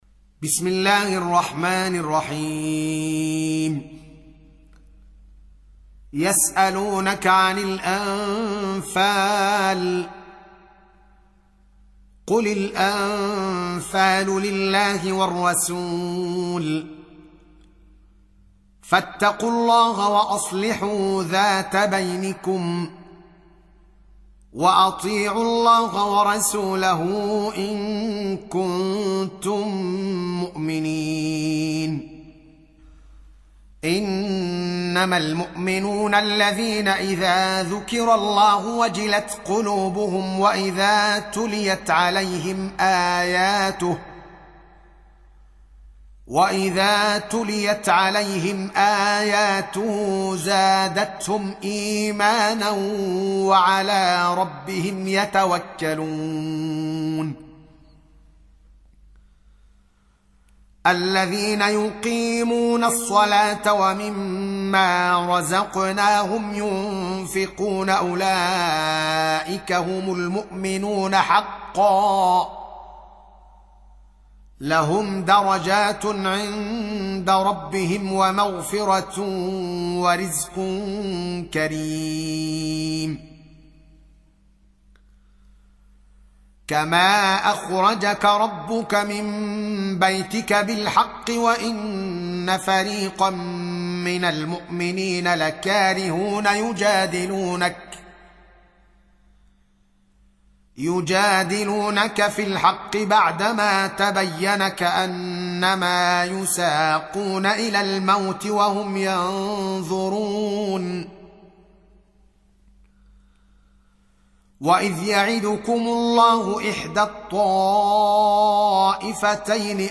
8. Surah Al-Anf�l سورة الأنفال Audio Quran Tarteel Recitation
حفص عن عاصم Hafs for Assem